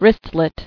[wrist·let]